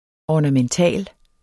Udtale [ ɒnaˈmənˈtæˀl ]